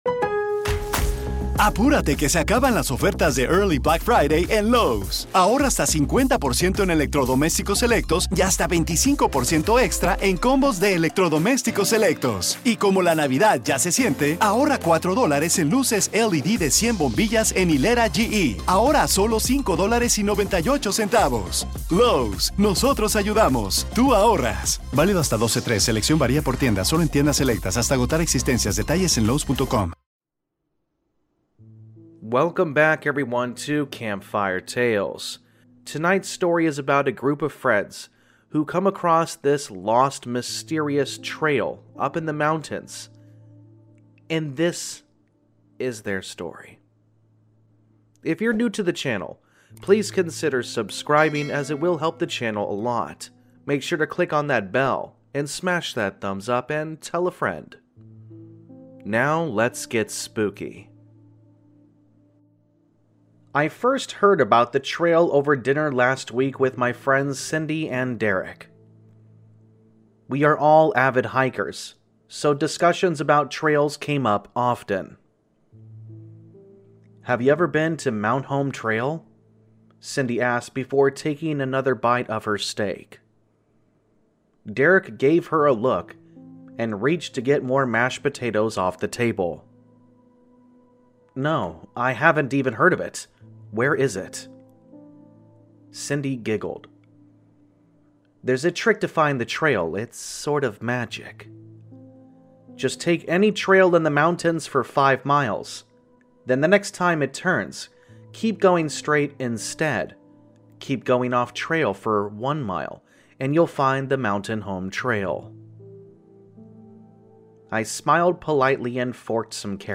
Sound Effects Credits
All Stories are read with full permission from the authors